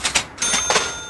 cash heal sound
cash_heal.ogg